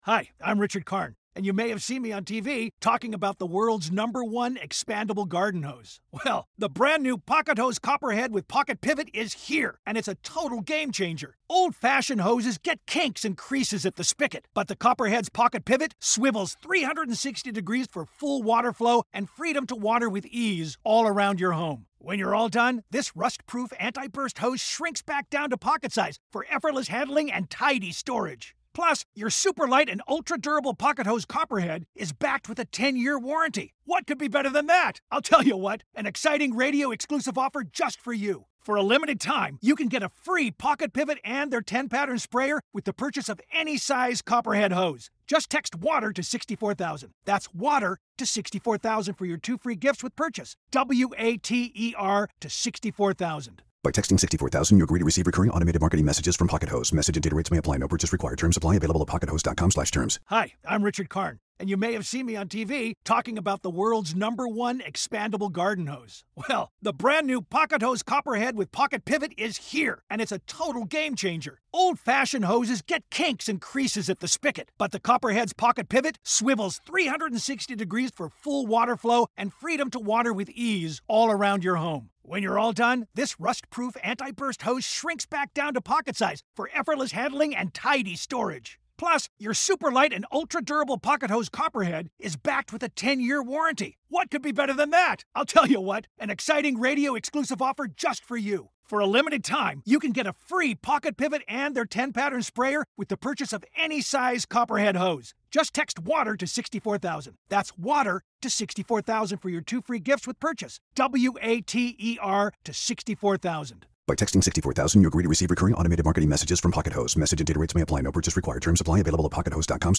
Tim Miller joins Charlie Sykes on today's podcast. Special Guest: Tim Miller.